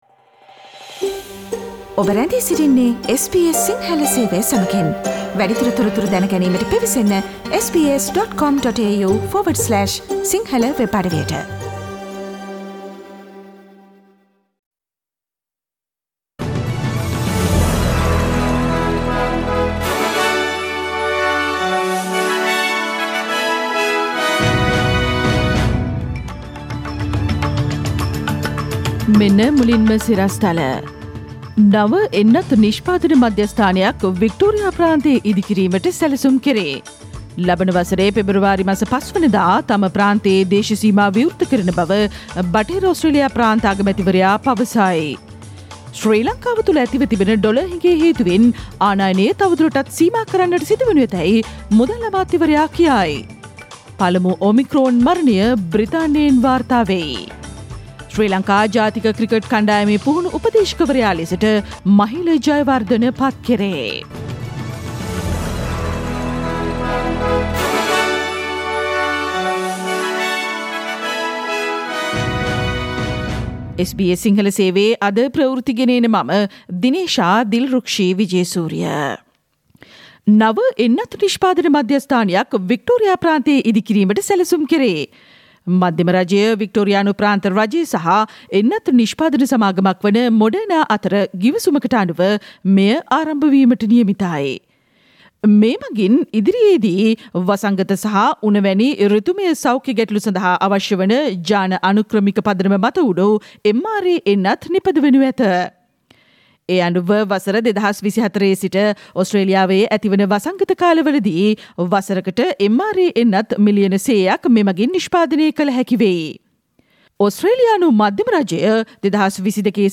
Click on the speaker icon on the photo above to listen to the SBS Sinhala Radio news bulletin on Tuesday 14th of December 2021